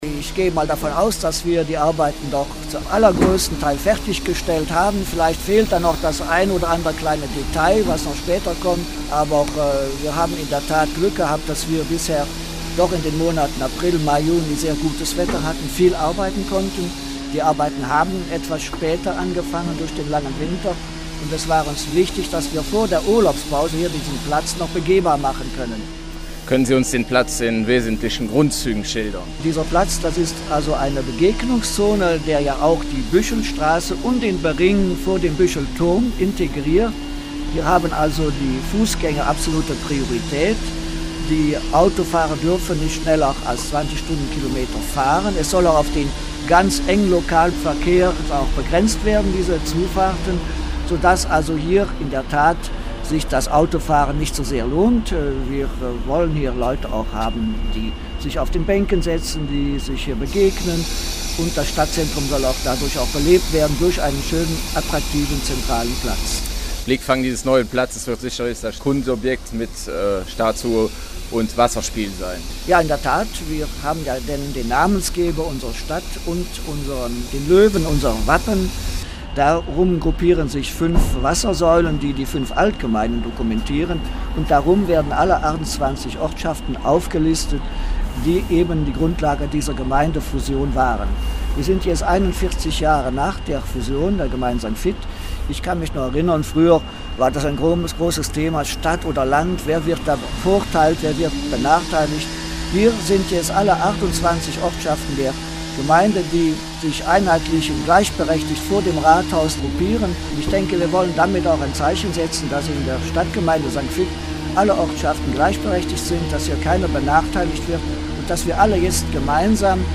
Der St.Vither Bürgermeister Christian Krings fiebert dem kommenden Sonntag entgegen. Bis dahin wird noch fleißig gewerkelt.